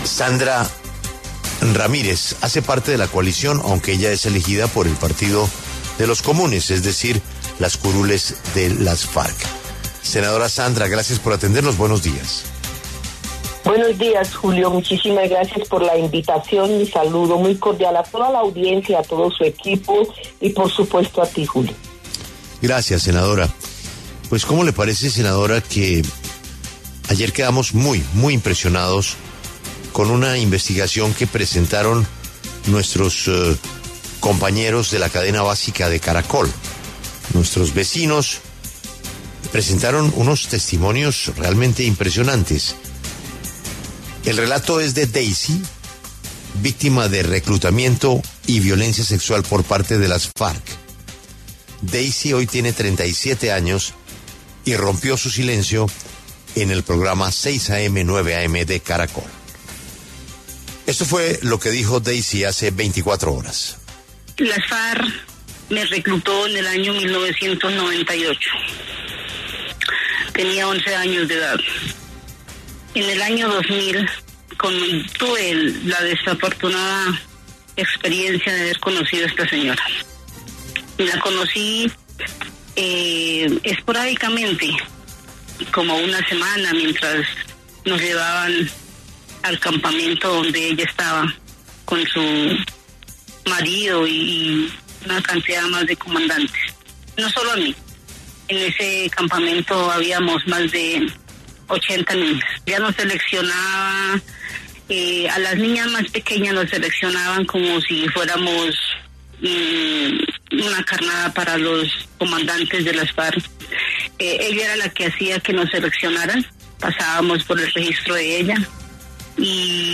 La senadora Sandra Ramírez, del Partido Comunes, respondió en La W por las denuncias en su contra sobre presunta violencia sexual al interior de las extintas Farc.